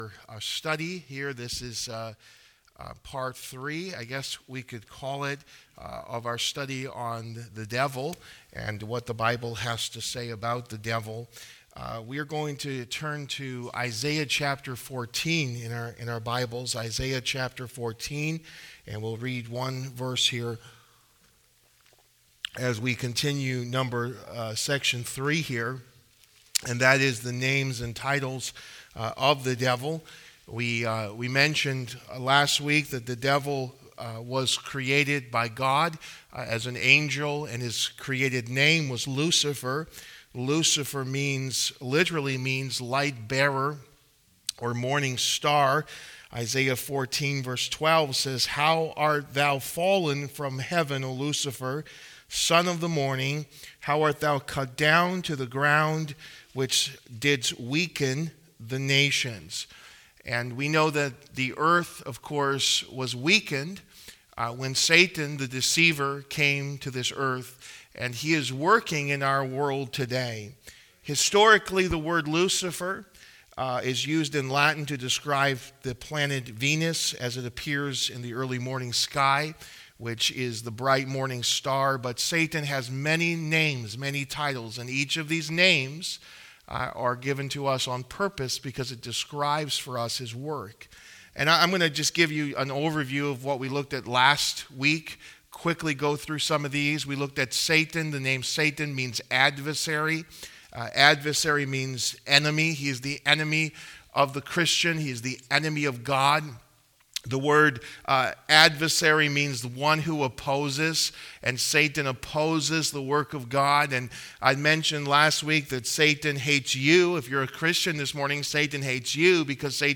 A Study on the Devil (Pt 1) | Sermons